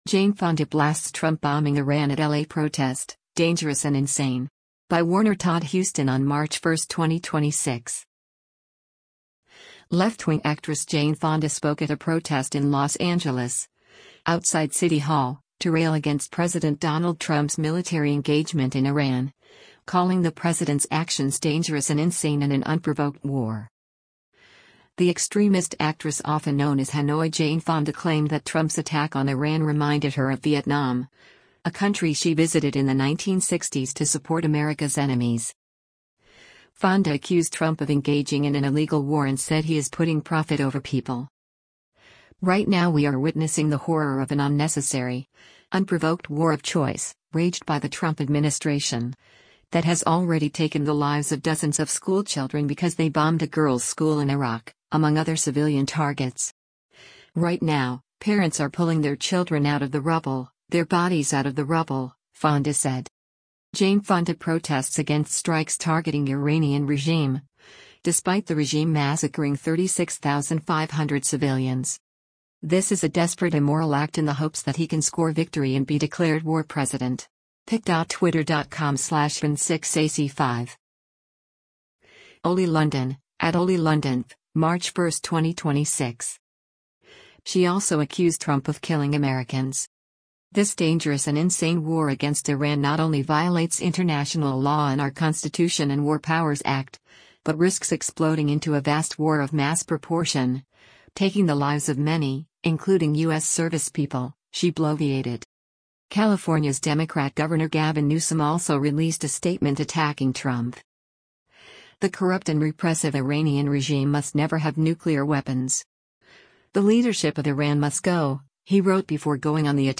Actress Jane Fonda speaks during a protest against war in Iran at City Hall in Los Angeles